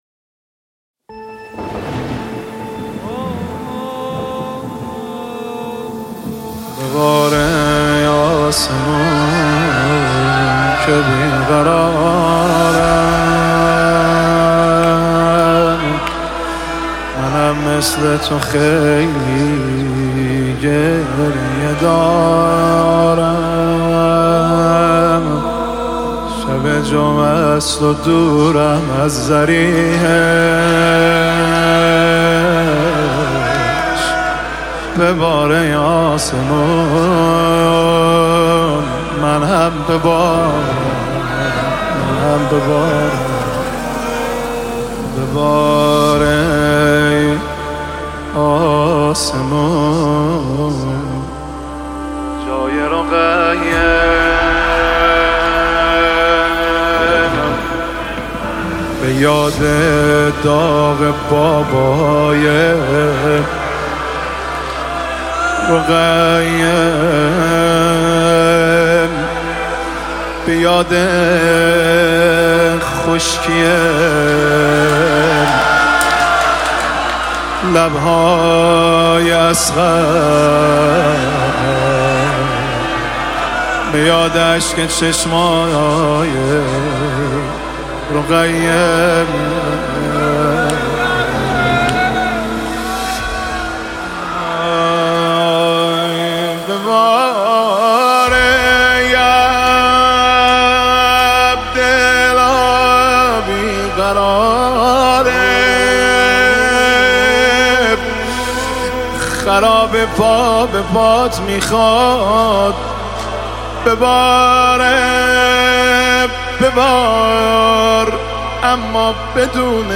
مداحی شهادت حضرت رقیه